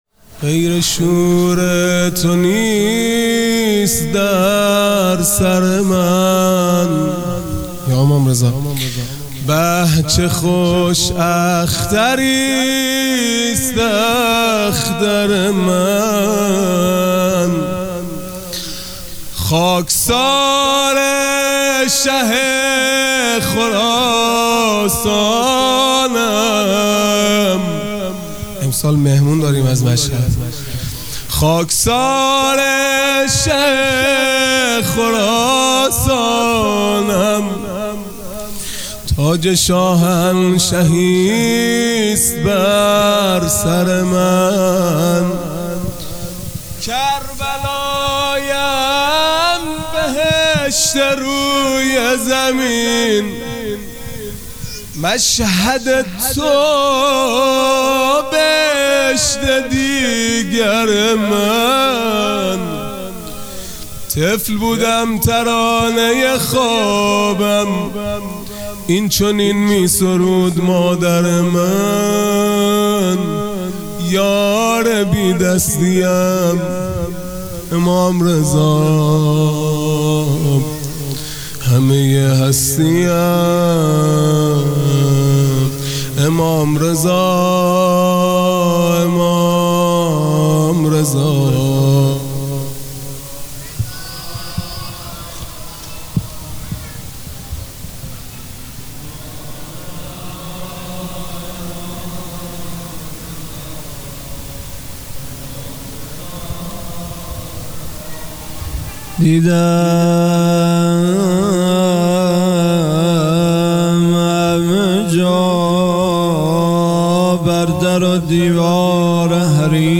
مناجات پایانی | پی شور تو نیست در سر من | ۳ مرداد ۱۴۰۲
محرم الحرام ۱۴۴5 | شب هشتم | سه شنبه 3 مرداد ماه ۱۴۰2